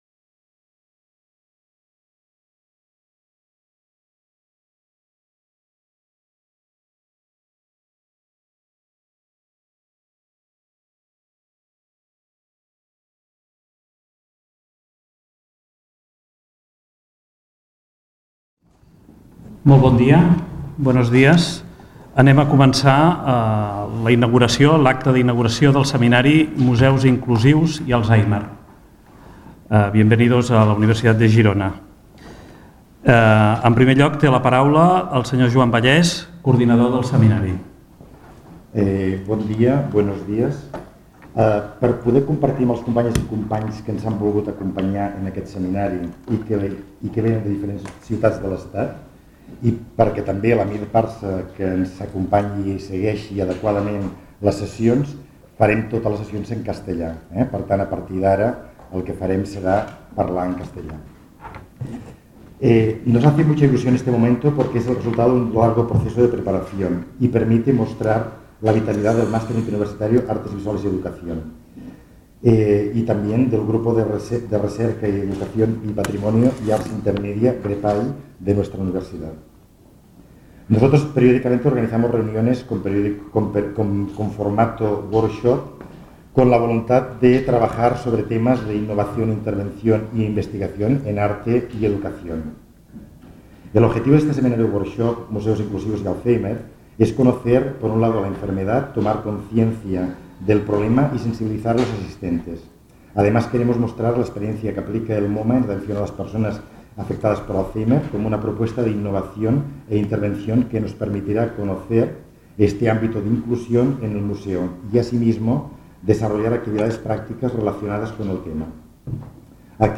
Inauguració del seminari 'Museus inclusius i Alzheimer'
01-inauguracio.mp3